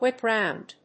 アクセントwhíp‐ròund